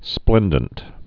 (splĕndənt)